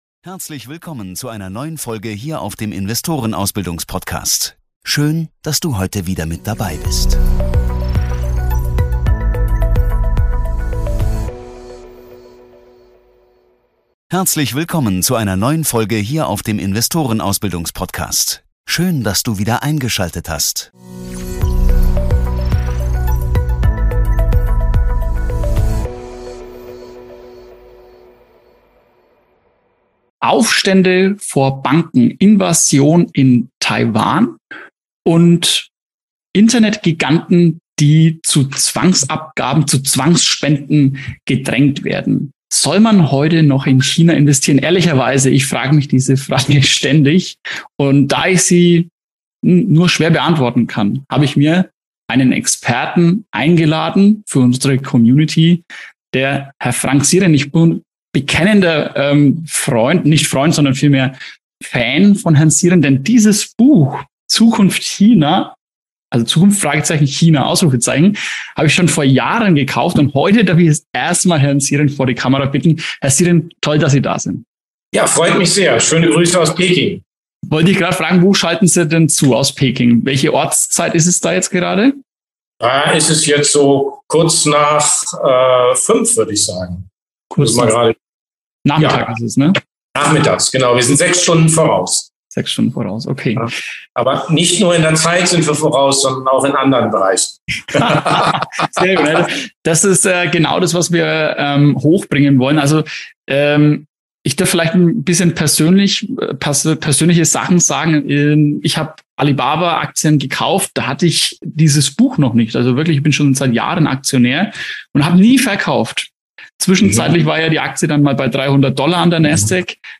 Im heutigen Interview habe ich einen der führenden Chinaexperten zu Gast, Frank Sieren. Er erklärt uns die aktuelle Wirtschaftslage in China und wie es mit der Politik aussieht.